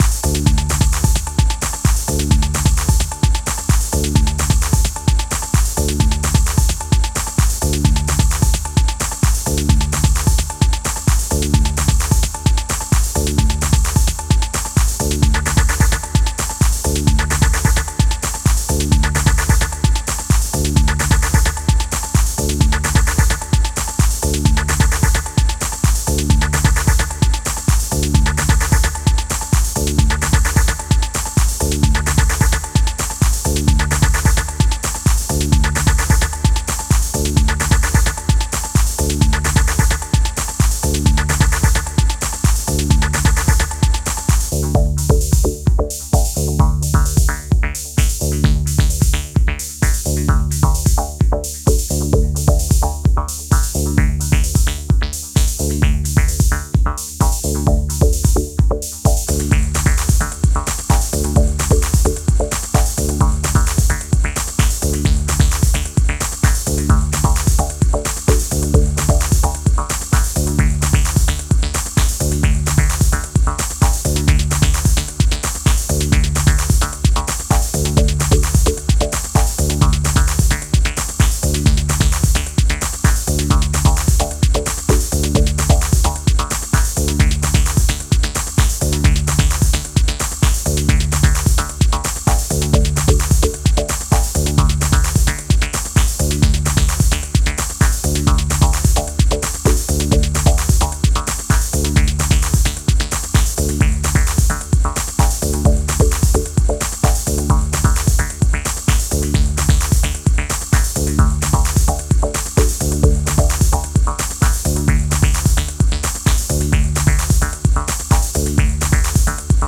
一歩引いたテンションでハメてくる、マシーナリーなグルーヴが渋いディープ・エレクトロ